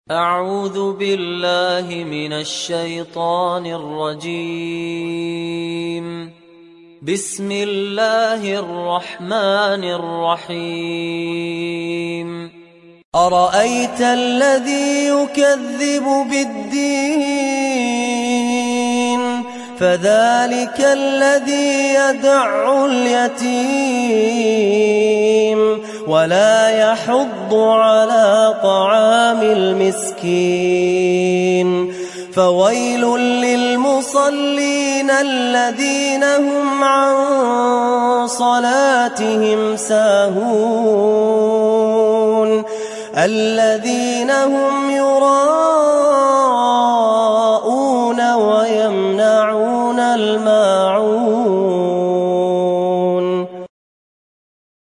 تحميل سورة الماعون mp3 بصوت فهد الكندري برواية حفص عن عاصم, تحميل استماع القرآن الكريم على الجوال mp3 كاملا بروابط مباشرة وسريعة